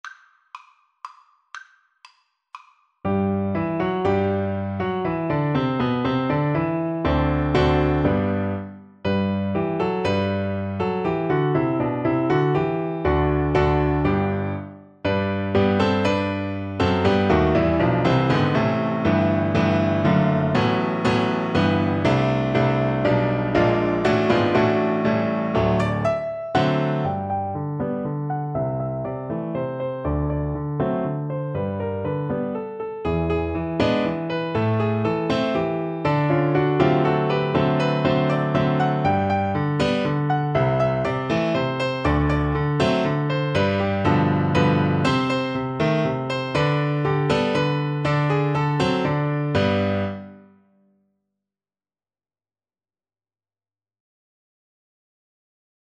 Allegro (View more music marked Allegro)
3/4 (View more 3/4 Music)
Classical (View more Classical Viola Music)
Cuban